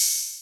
TM88 RacksOpen-Hat.wav